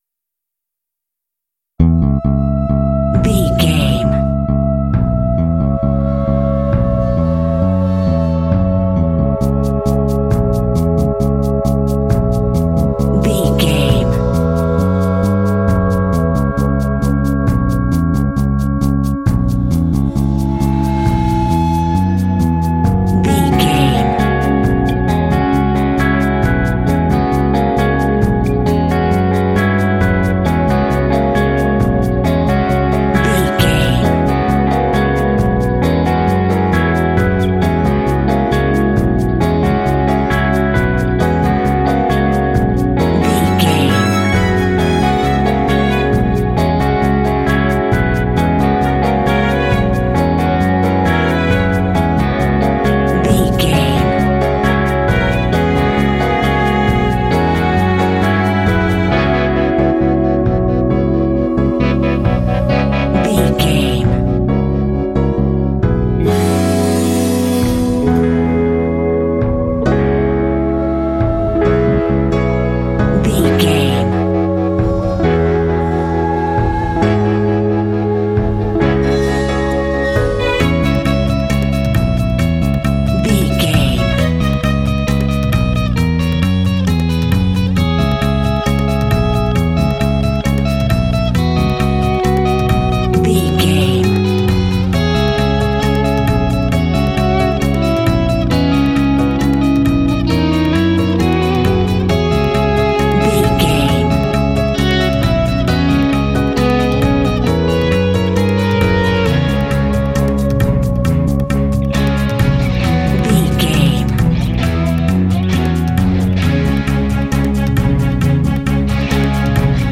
Epic / Action
Aeolian/Minor
C#
dramatic
foreboding
tension
electric guitar
synthesiser
percussion
bass guitar
piano
strings
orchestral
film score